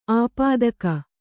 ♪ āpādaka